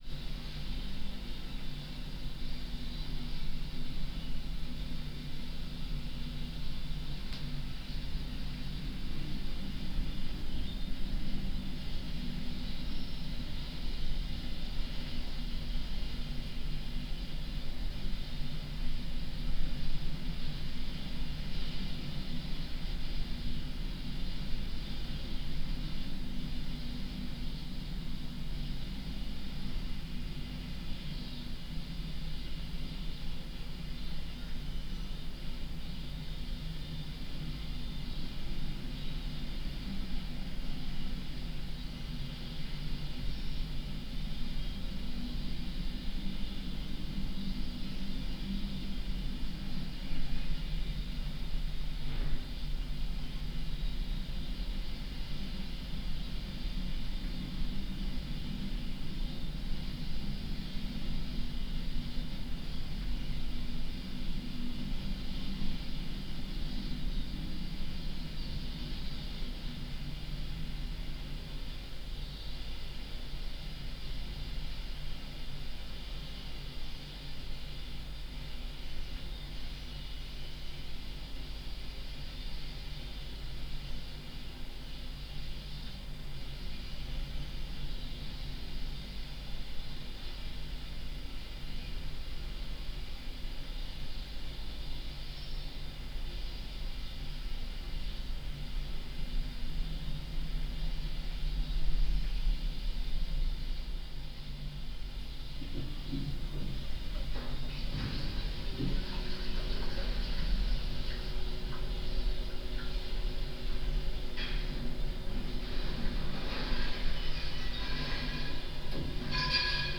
This is a compilation from the ongoing project ‘Situations and Circumstances’ as a series of field recordings that are made public in this release intending to be available for free download and potential reuse in new works of other artists.